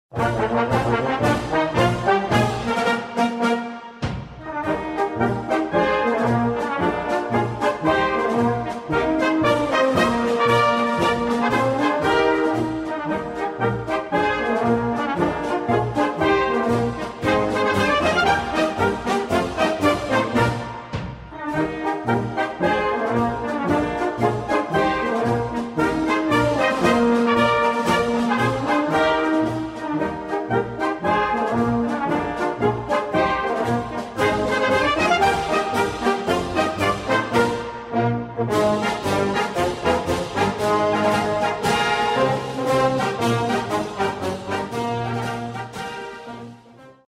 Besetzung: Blasorchester
Ein neuer, gefälliger Marsch